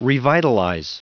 Prononciation du mot : revitalize